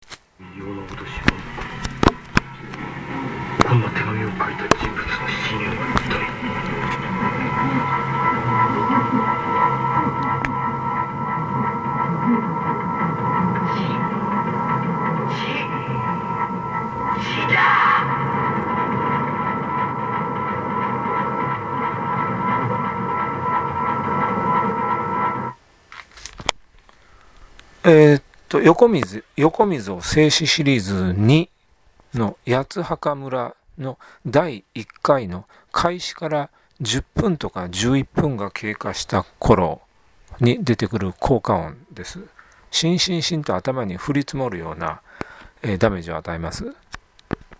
横溝正史シリーズII八墓村第一回内容開始から10分経過した頃の効果音 聴覚を経由して雪が降り積もる様に脳にシンシンと鬱積して行く音の模擬音です。 効果ではなく聞こえたままを言うなら「シンシン」ではなく「グワングワン」ぐらいでしょう。
高度成長期の田舎の小規模な多数の工場から出ていたであろうような音です。